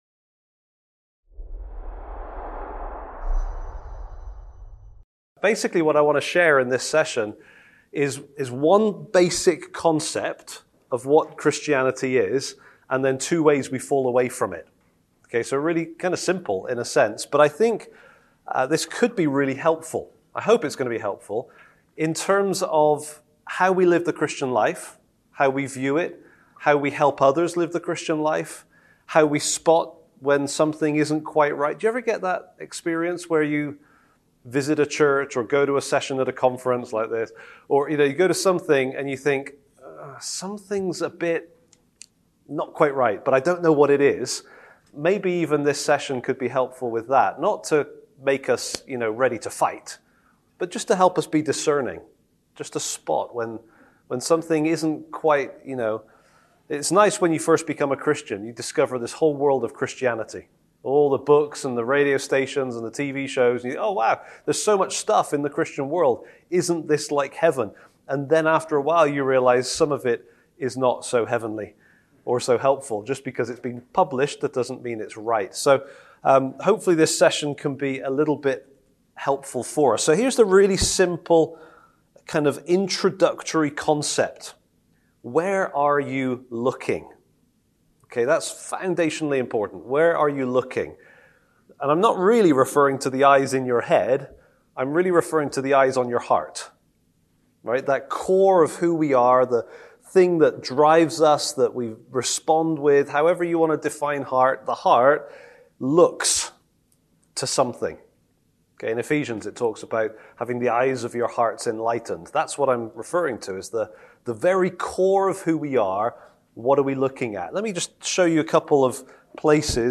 Event: ELF Workshop